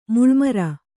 ♪ muḷmara